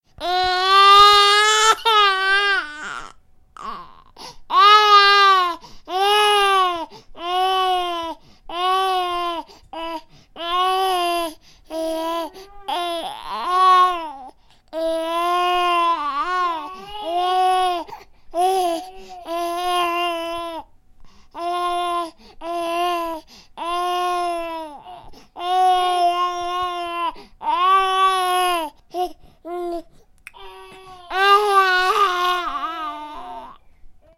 دانلود صدای گریه بچه از ساعد نیوز با لینک مستقیم و کیفیت بالا
جلوه های صوتی